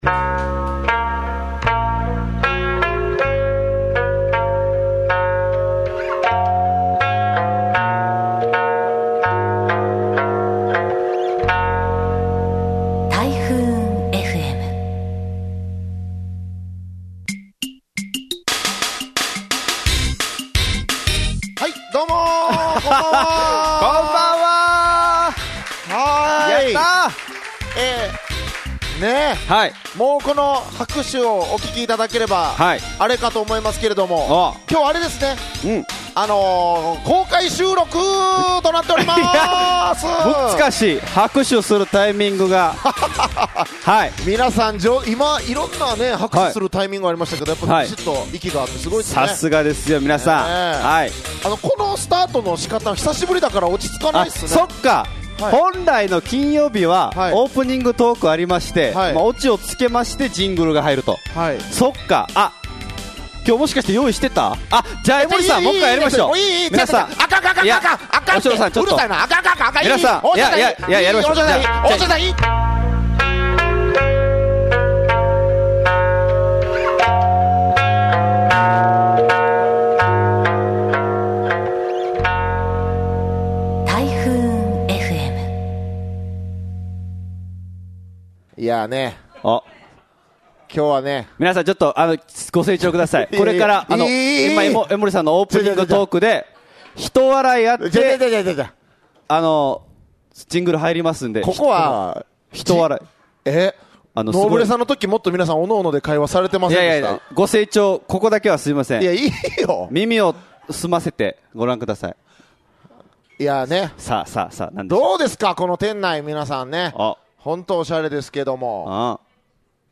160812リップサービスのオリジンアワー公開収録 in ハル晴ル